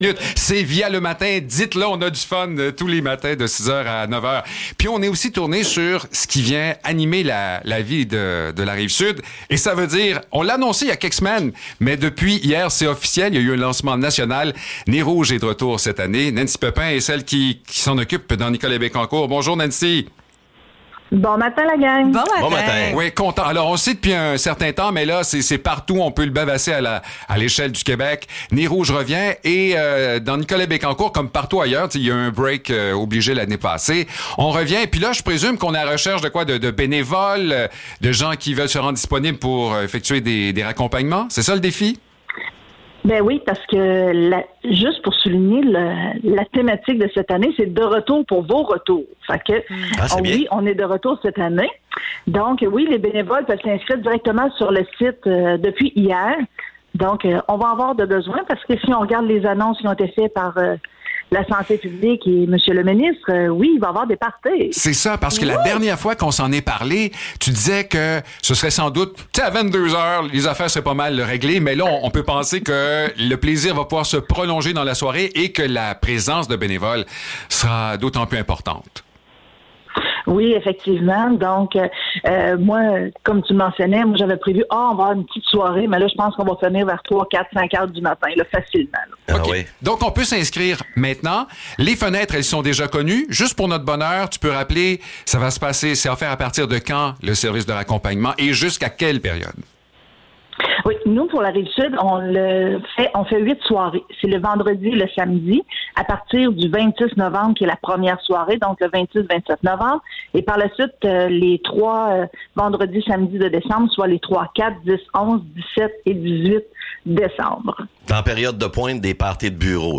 Entrevue pour le retour d’Opération Nez Rouge (3 novembre 2021)